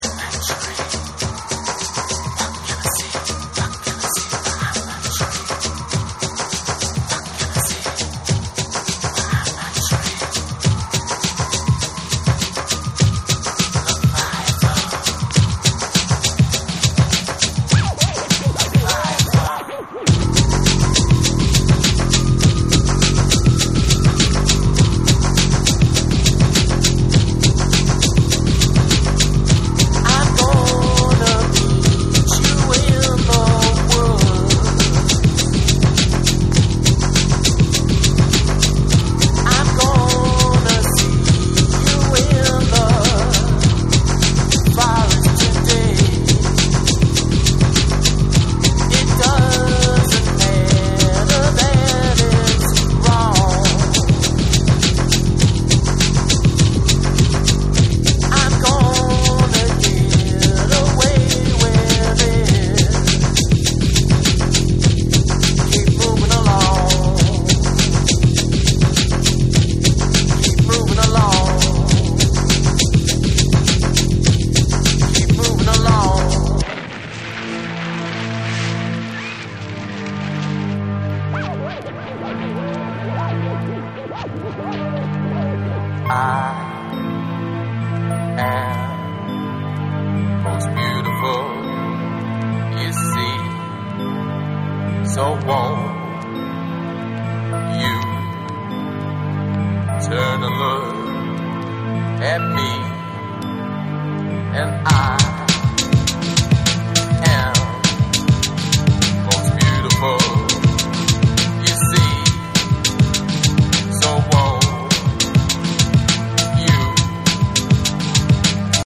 REGGAE & DUB / BREAKBEATS